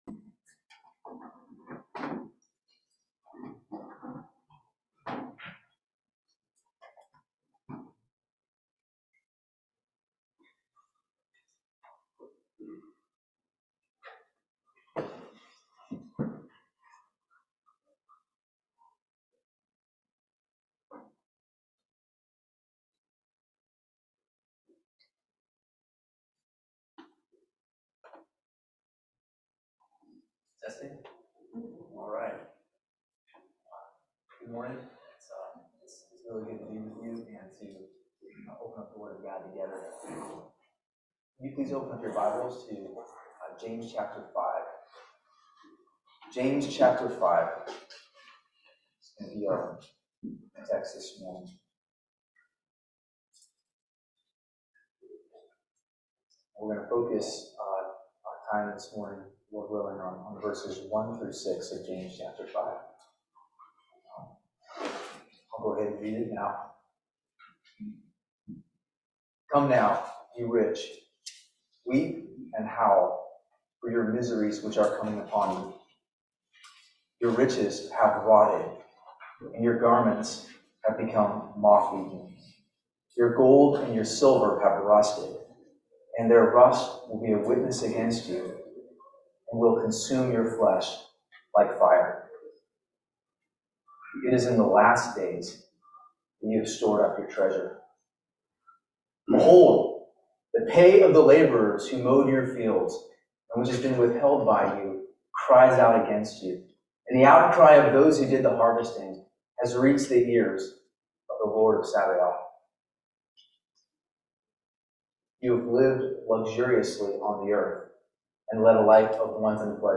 Teaching from James 5:1-6
Service Type: Family Bible Hour